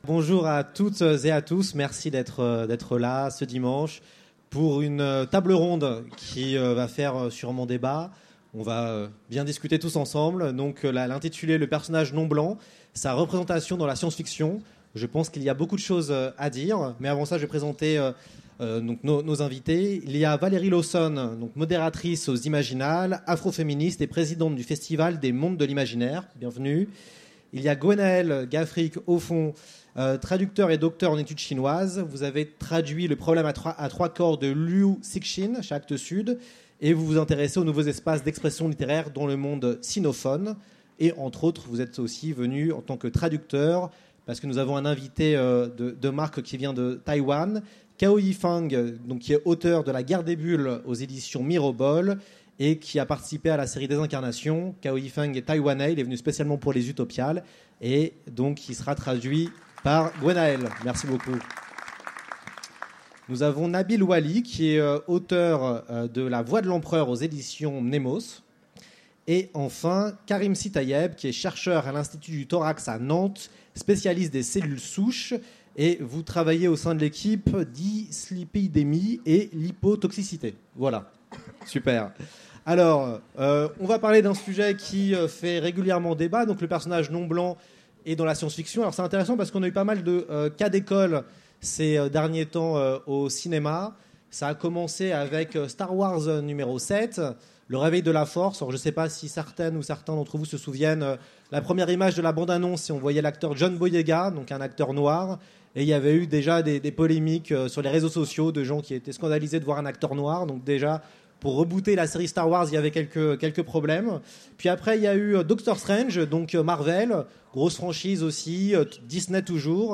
Utopiales 2017 : Conférence Le personnage non blanc : sa représentation dans la SF
Conférence